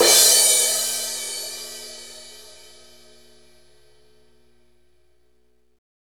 Index of /90_sSampleCDs/Northstar - Drumscapes Roland/DRM_Hip-Hop_Rap/CYM_H_H Cymbalsx